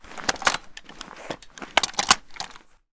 m16_reload.ogg